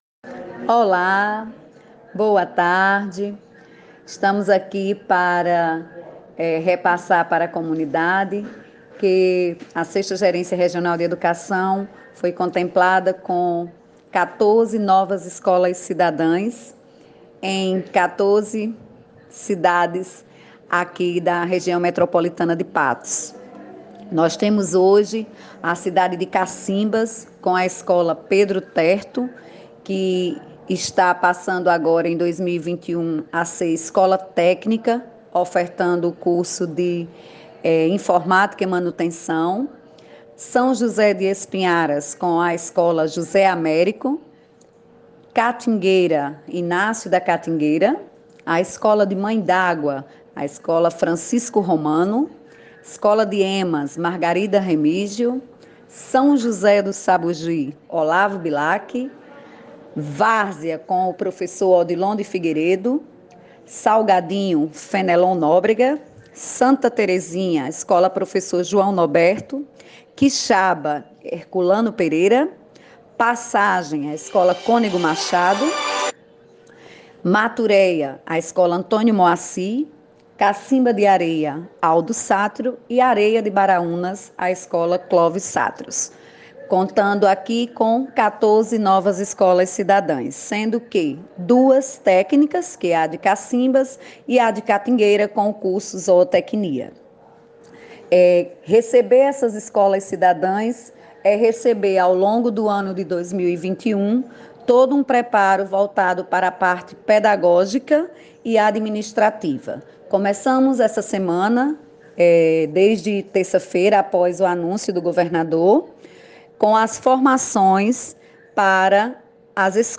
Fonte: Programa Hora Onze (Rádio Universidade FM)